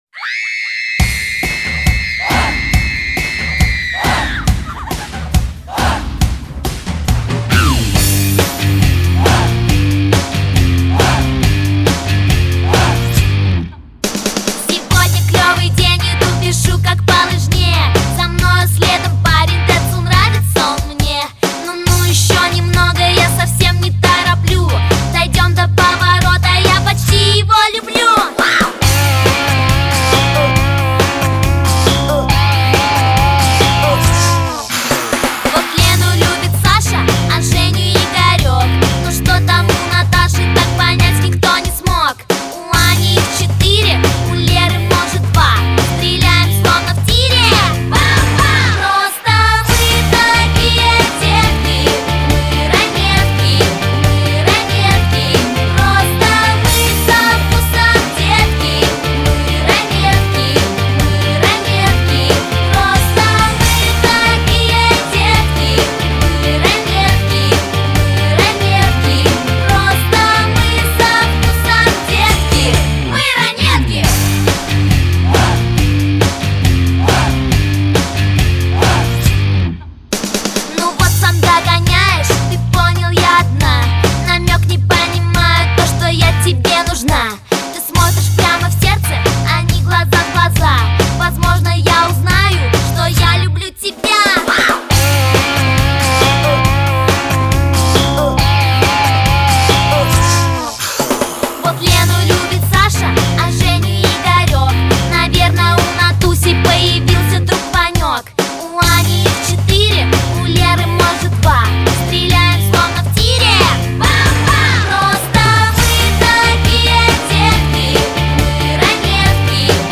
Стиль: Pop